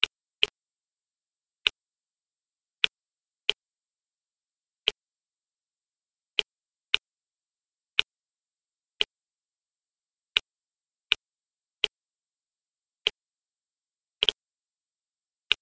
geiger_level_0.ogg